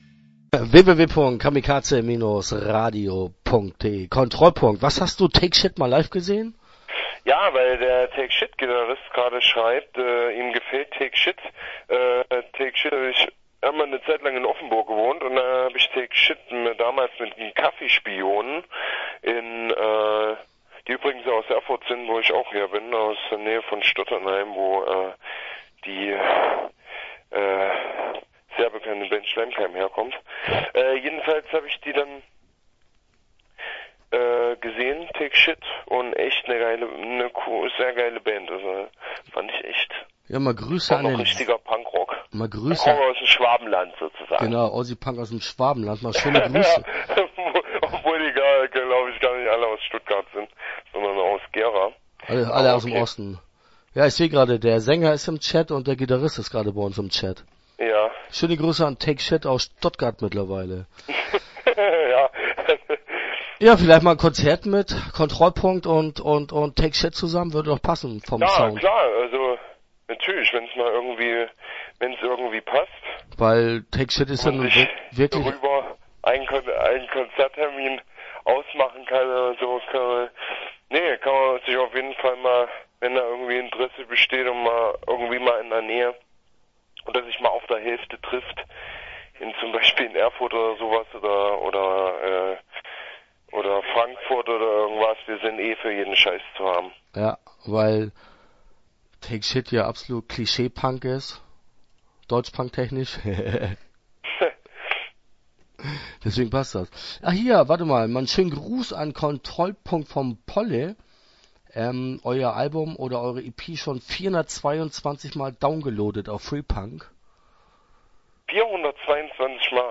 Kontrollpunkt - Interview Teil 1 (7:49)